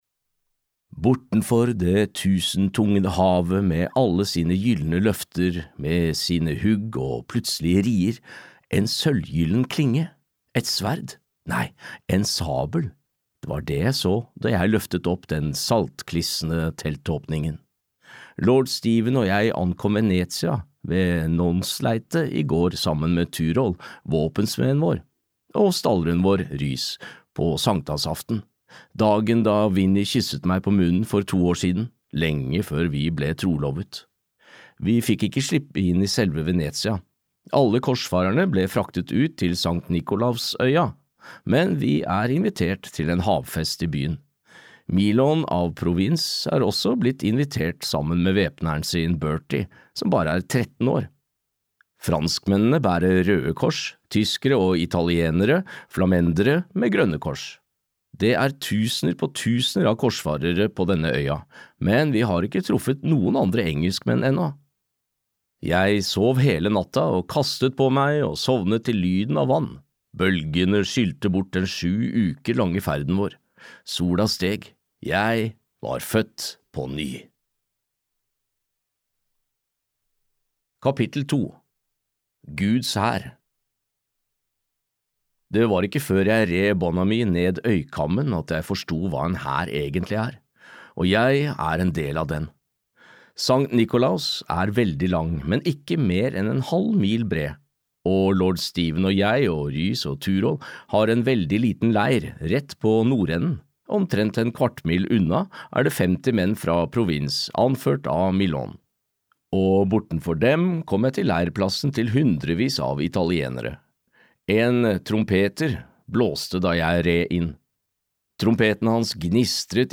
Arthur - konge av Midtmyrene (lydbok) av Kevin Crossley-Holland